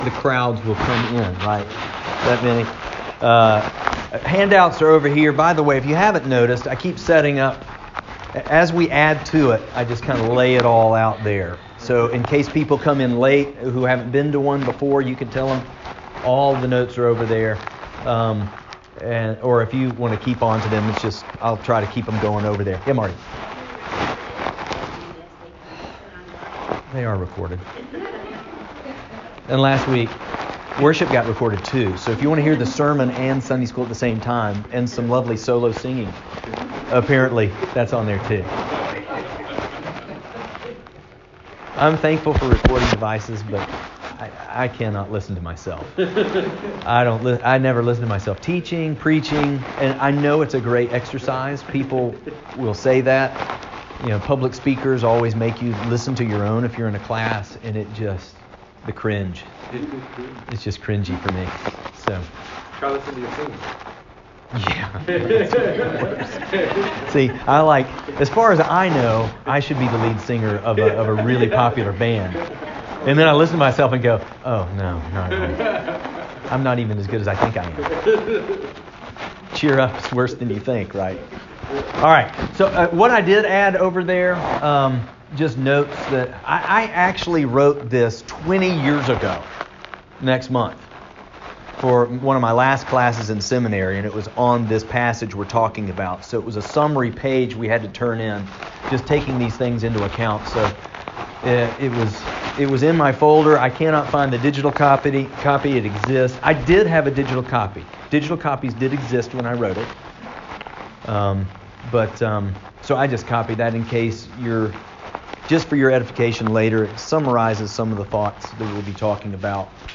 Sermon on the Mount Service Type: Sunday School %todo_render% « God Using Discouragement A Life Vision